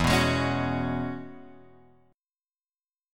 E6add9 chord {0 2 x 1 2 2} chord